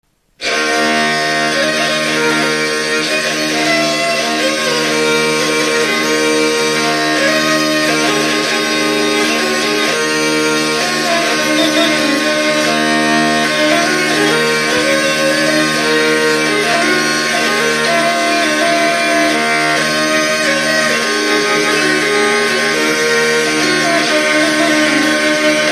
Dallampélda: Hangszeres felvétel
Alföld - Csongrád vm. - Szentes
tekerő Műfaj: Csendes Gyűjtő